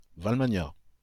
Valmanya (French pronunciation: [valmaɲa]